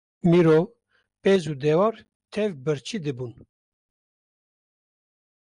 Pronounced as (IPA) /pɛz/